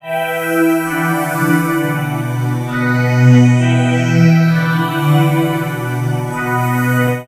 Cudi Synth 2.wav